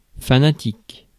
Ääntäminen
France: IPA: /fa.na.tik/